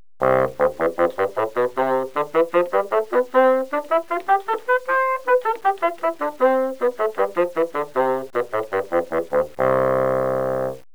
A 3-octave scale on a bassoon is presented,